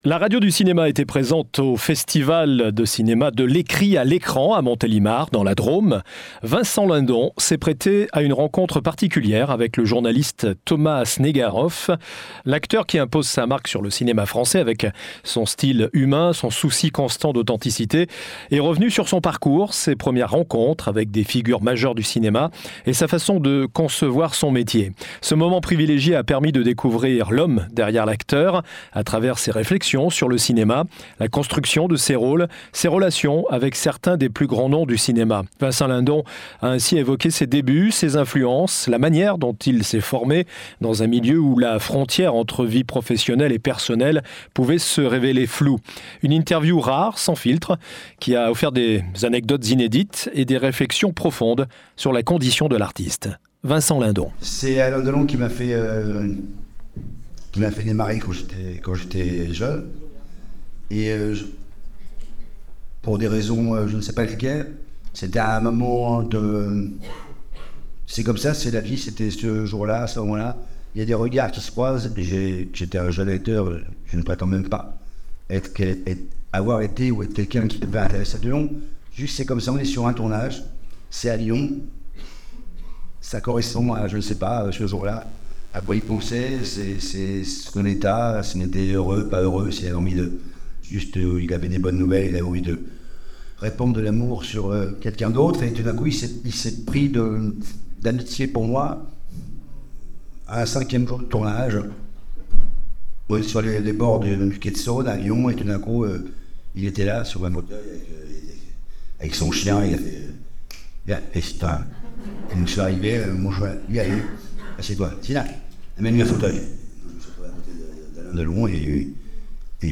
Ce samedi 21 septembre, lors d'une conversation ouverte au Festival de l'Ecrit à l'écran, Vincent- Lindon a partagé ses réflexions sur son métier, son engagement envers son art et son désir de tisser des liaisons authentiques avec les autres, un échange mené d'une main de maître par le présentateur Thomas Snegaroff.